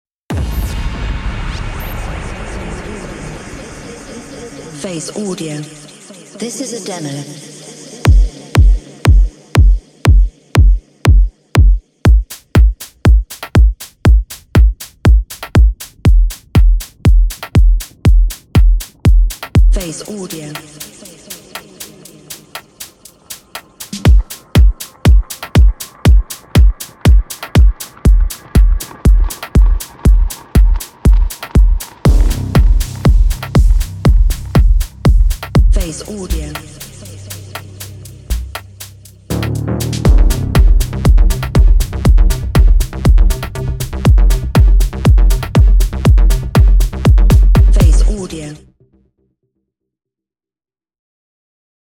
Avoid wasting hours of time and energy fixing a kick, these kicks are top industry and won’t need any corrective processing, just the creative touch you decide as producer.
Unleash your creativity: • This sample pack contains 25 key labeled mastering grade kick samples in the style of Afterlife. • Analog processed for maximum voltage at public addressed line array systems. • All kicks are free of noise, clicks, crackling, springs, or any other distractive artifacts that escalate during mastering.